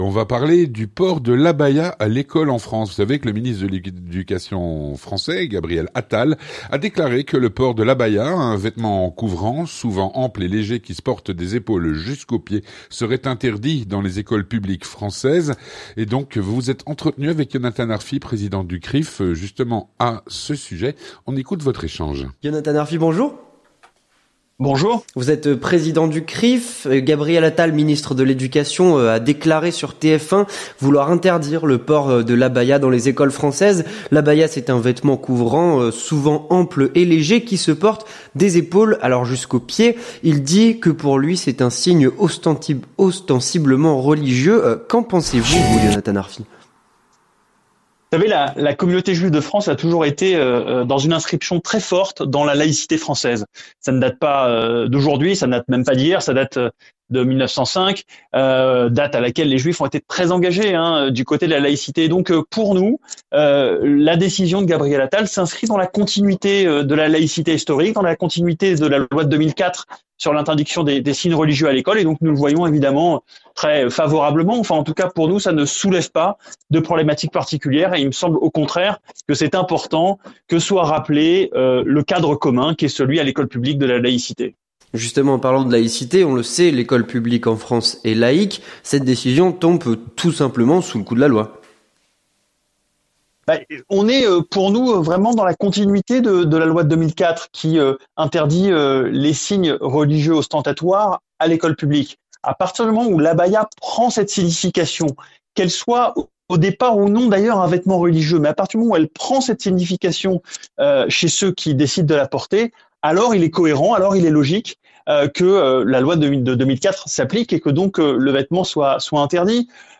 L'entretien du 18H - Le port de l’abaya à l’école en France.